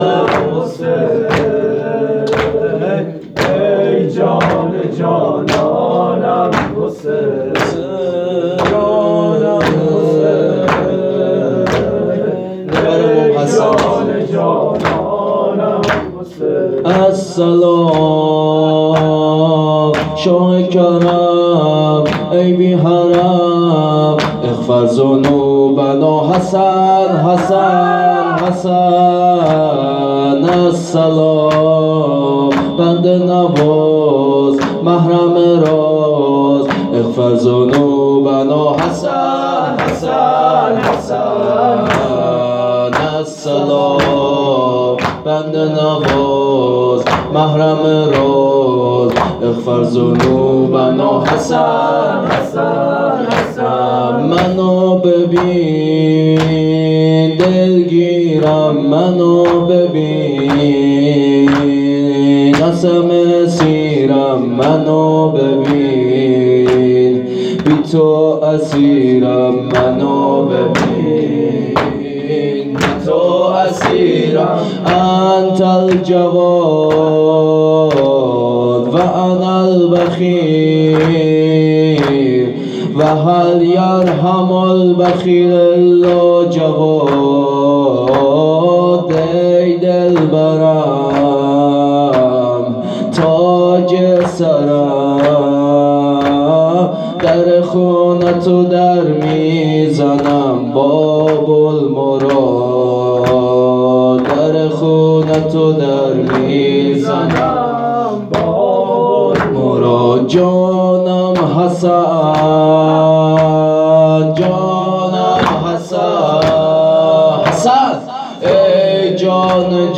گزارش صوتی جلسه
[زمینه،السلام،شاه کرم،ای بی حرم]
[مراسم هفتگی97/2/27]